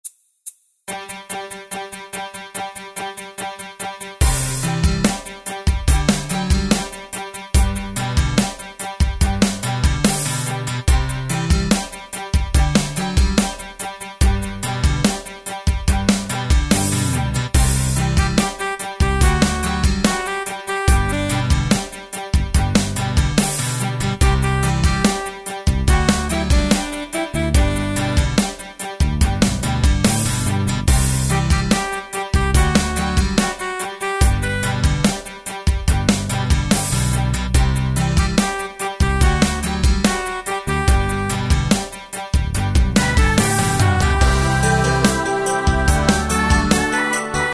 Tempo: 72 BPM.
MP3 with melody DEMO 30s (0.5 MB)zdarma